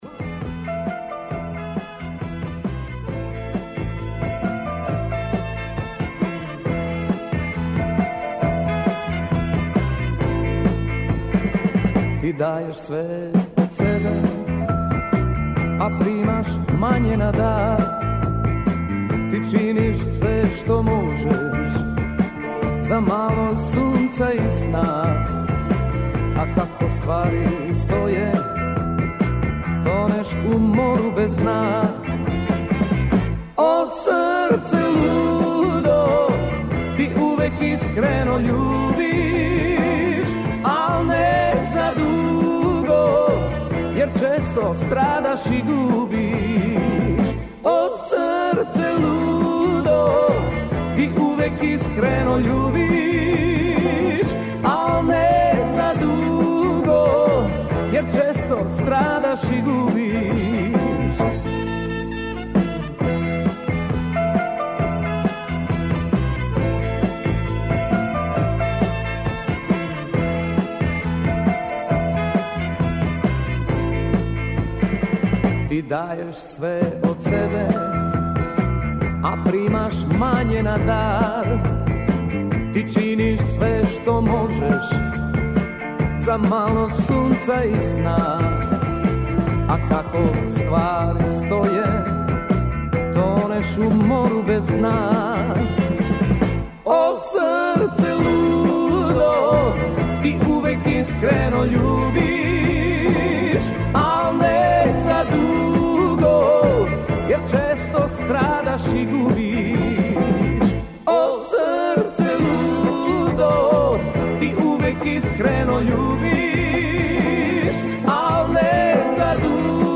Прозвучала на радио в программе